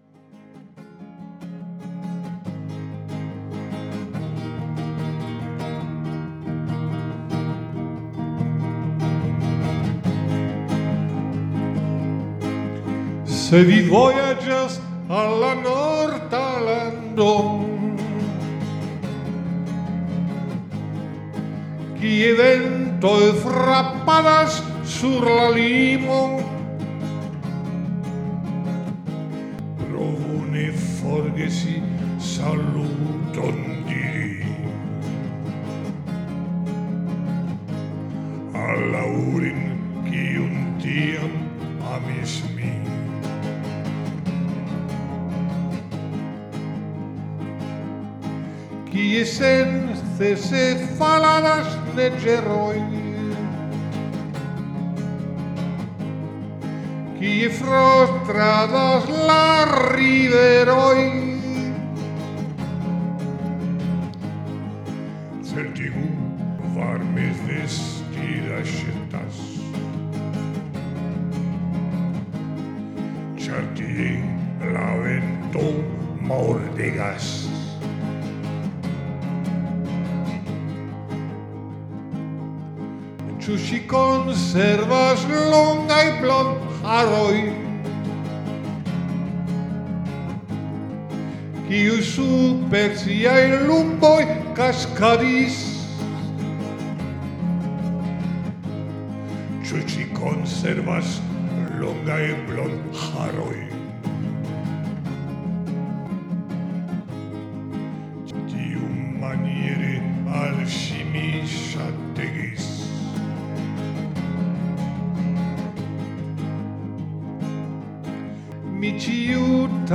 [Capo 1°]